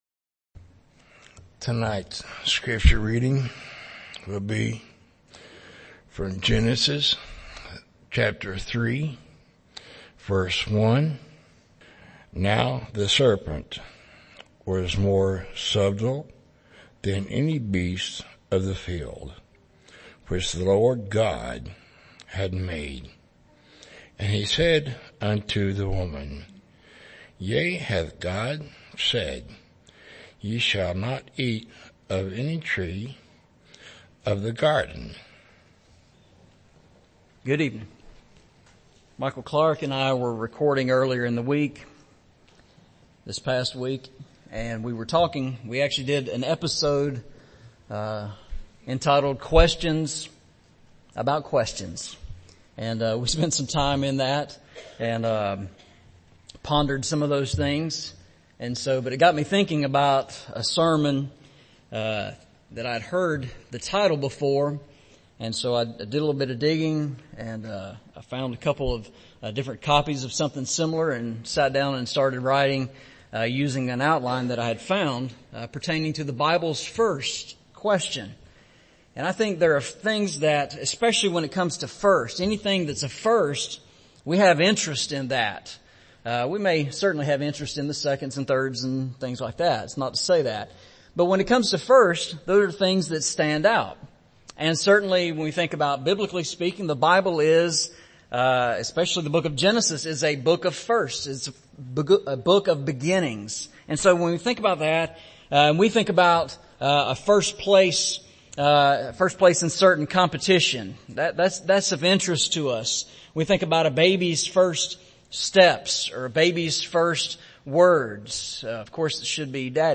Eastside Sermons Service Type: Sunday Evening Preacher